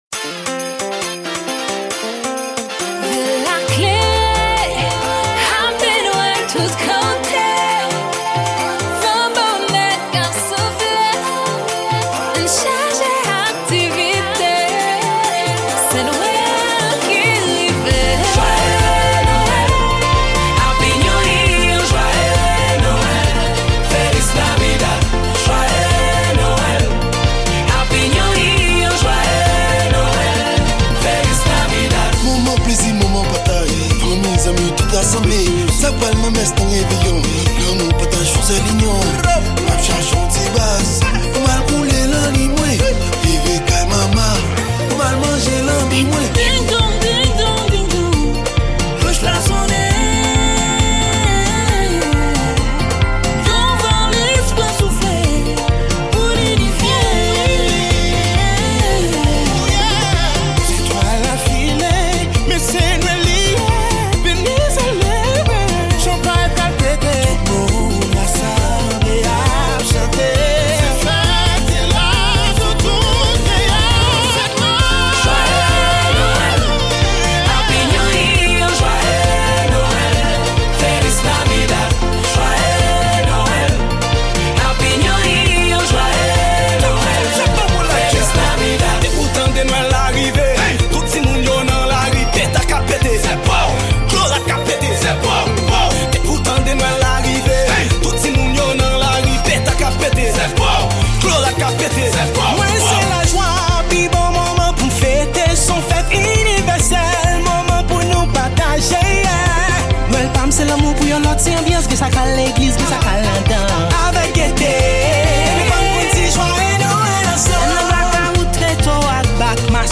Très belle chanson de Noel